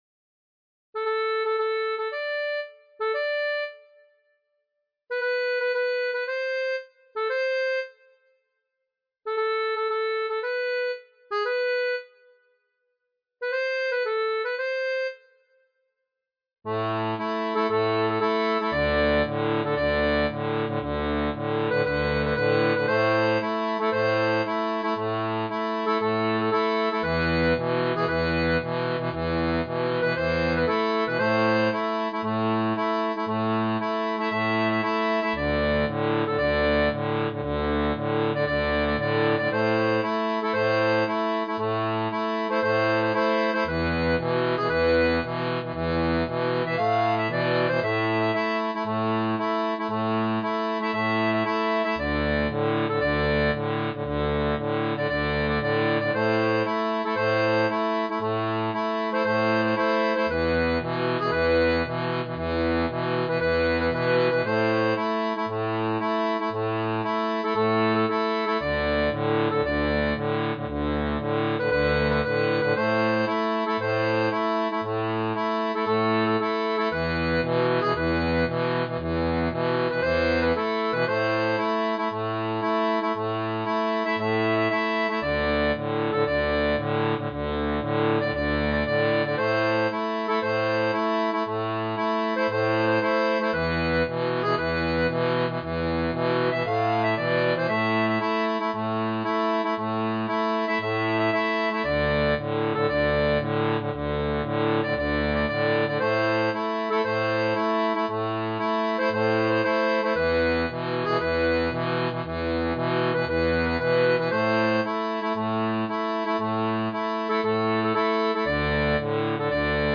• Une tablature pour diato à 2 rangs transposée en La
Chanson française